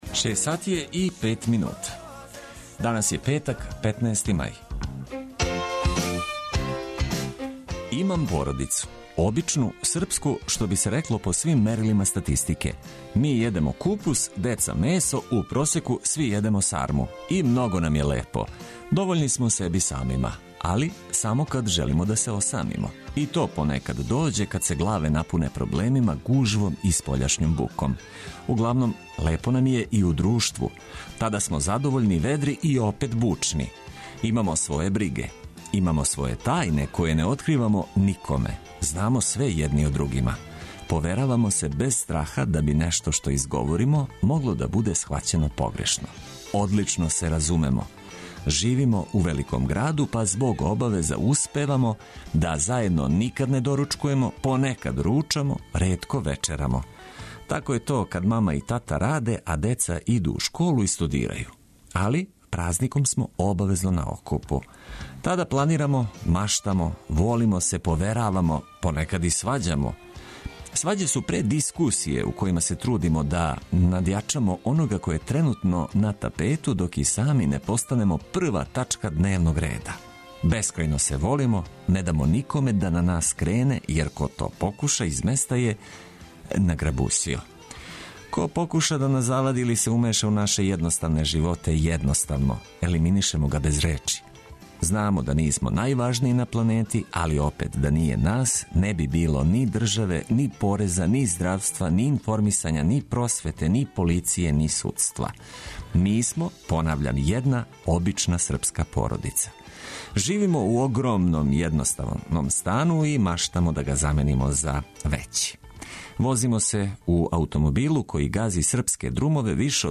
Кад дан почне уз много добре музике прошаране информацијама од користи за већину слушалаца онда свакодневне обавезе лакше "падну".